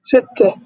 spoken-arabic-digits